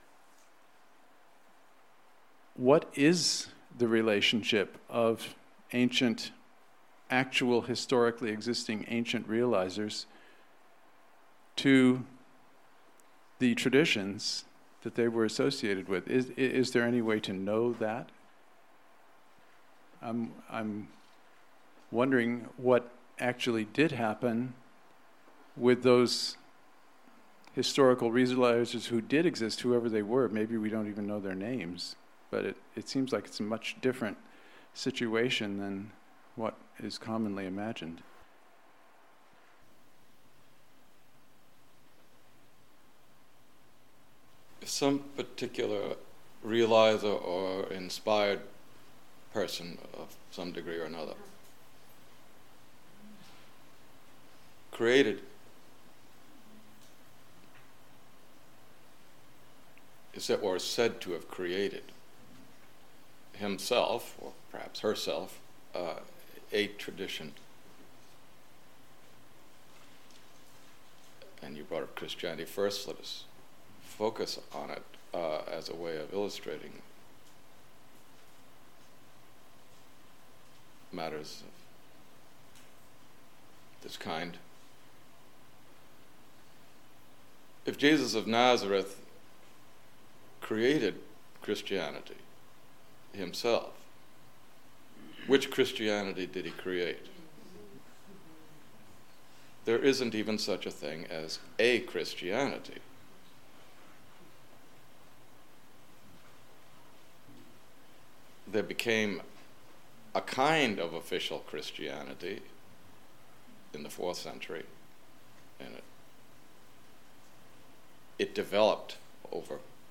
On November 25, 2004, at Adi Da Samrajashram, one of Adi Da's devotees asks Him: "Is there any way to know . . . what is the relationship of ancient Realizers to the traditions that they were associated with?"
During these occasions, Avatar Adi Da answered questions from those present in the room with Him, but also from devotees all over the world via speakerphone.
• Tags: avataric discourse   CD